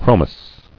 [chro·mous]